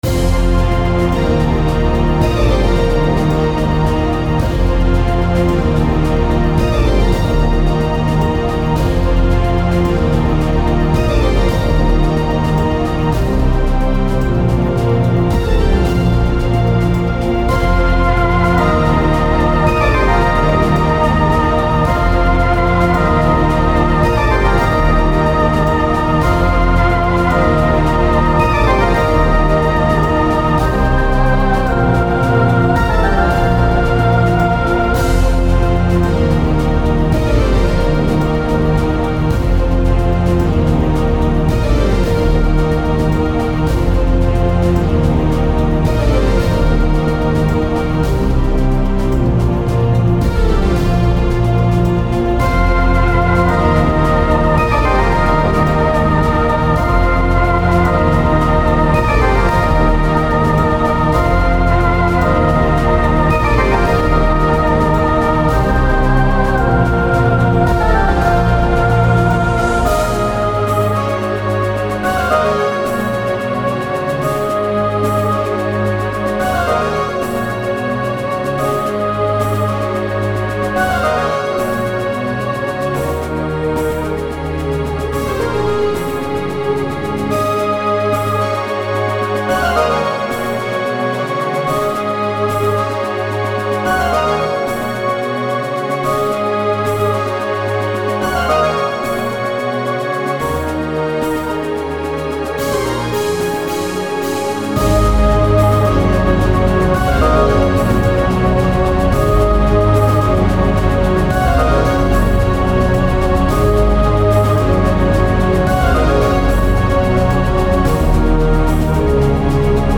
Music / Game Music
loop